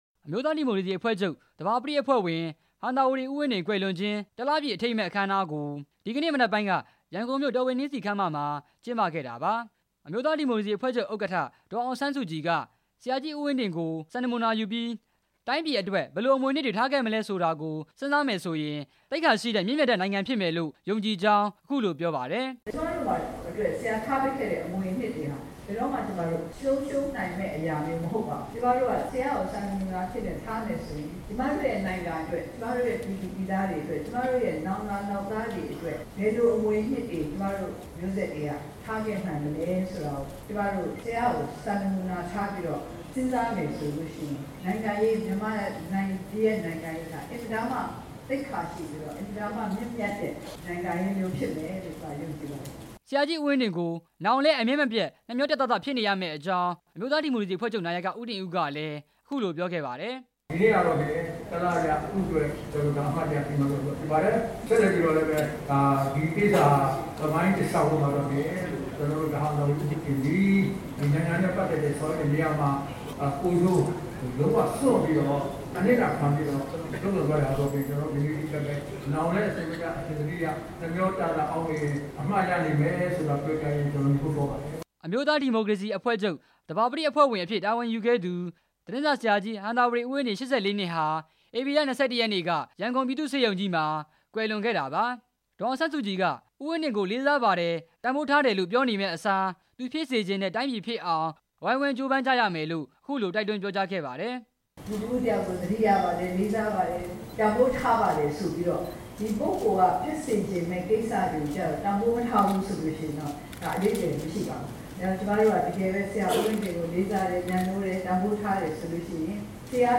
အမျိုးသားဒီမိုကရေစီအဖွဲ့ချုပ် သဘာပတိ အဖွဲ့ဝင် အဖြစ် တာဝန်ယူခဲ့သူ ဟံသာဝတီ သတင်းစာ ဆရာကြီး ဦးဝင်းတင် တစ်လပြည့် အခမ်းအနားကို ဒီနေ့ ရန်ကုန်မြို့ တော်ဝင်နှင်းဆီခန်းမမှာ ကျင်းပခဲ့ပြီး အမျိုးသားဒီမိုကရေစီအဖွဲ့ချုပ် ဥက္ကဌ ဒေါ်အောင်ဆန်း စုကြည်က ဆရာ ဦးဝင်းတင်အတွက် အမှတ်တရ စကား ပြောကြားခဲ့ပါတယ်။